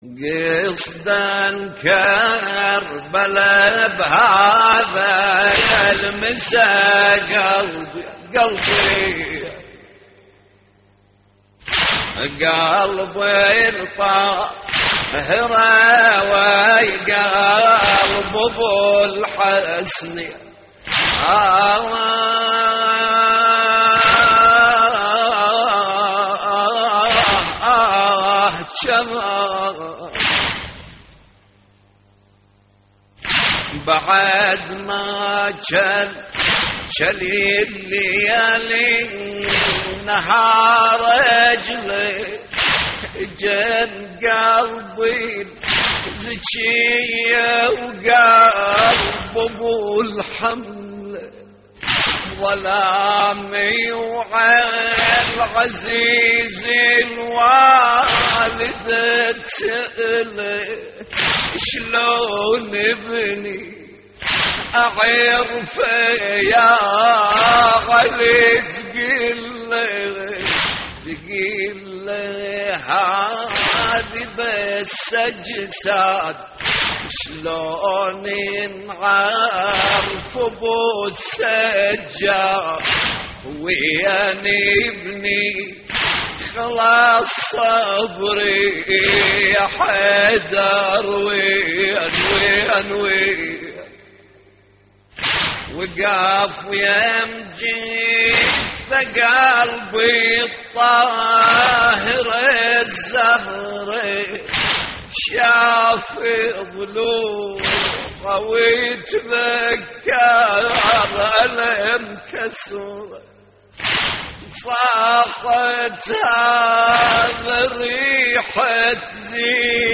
اللطميات الحسينية
موقع يا حسين : اللطميات الحسينية قصدن كربلا بهذا المساء قلبين - استديو لحفظ الملف في مجلد خاص اضغط بالزر الأيمن هنا ثم اختر (حفظ الهدف باسم - Save Target As) واختر المكان المناسب